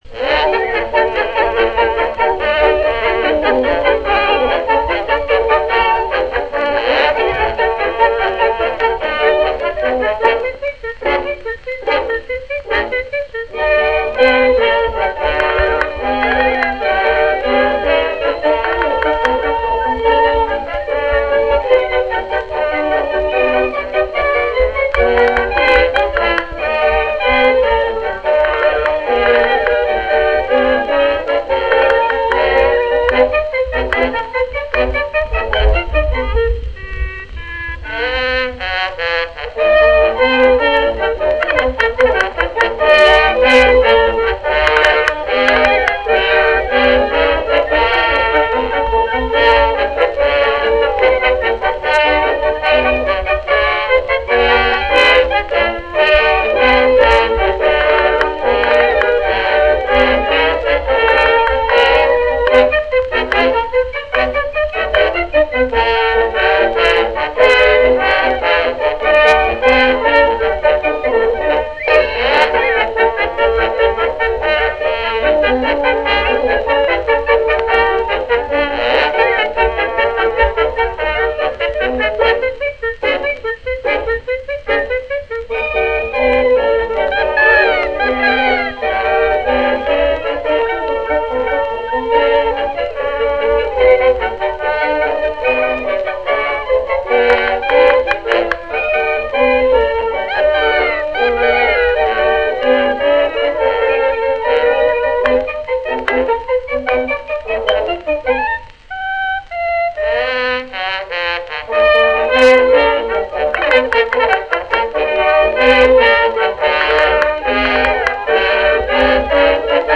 A British studio orchestra, conductor unknown.